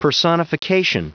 Prononciation du mot personification en anglais (fichier audio)
Prononciation du mot : personification